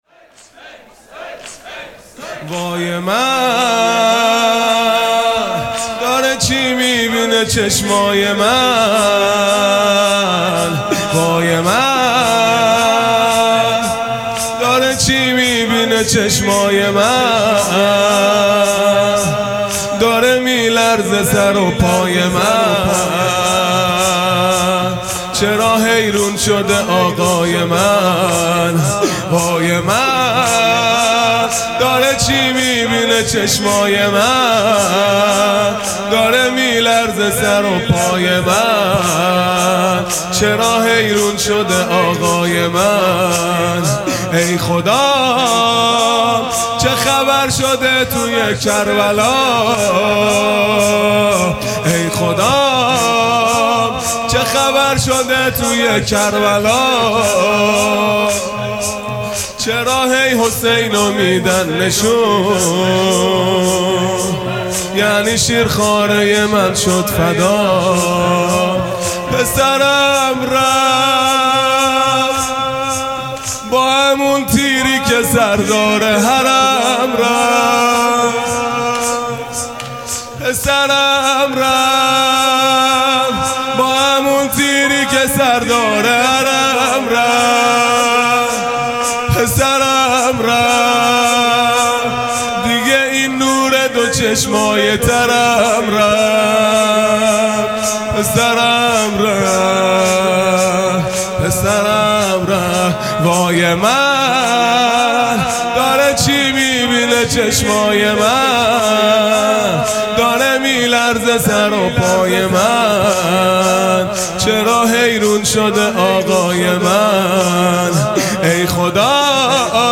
محرم الحرام ۱۴۴5 | شب هفتم